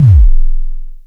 KLONE_C_KICKLO4NORM.wav